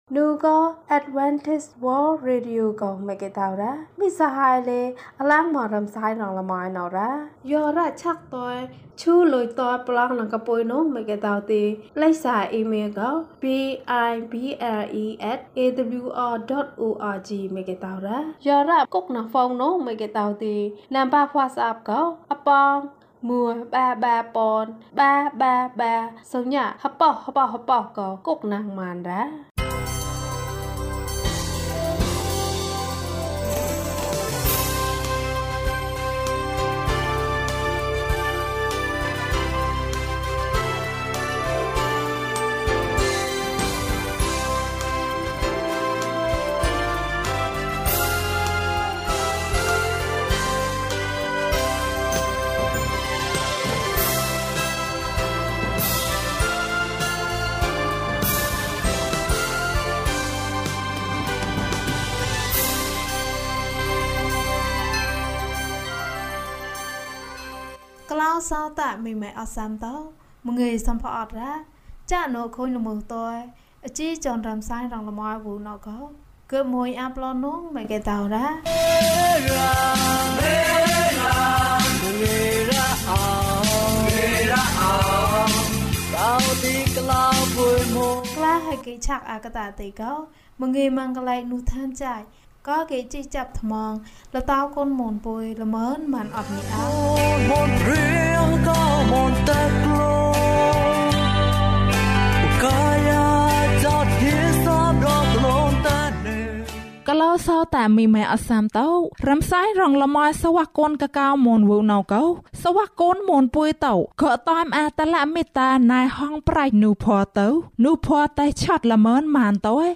ယေရှု၌ ကျွန်ုပ်တို့၏ဆုတောင်းချက်။၀၂ ကျန်းမာခြင်းအကြောင်းအရာ။ ဓမ္မသီချင်း။ တရားဒေသနာ။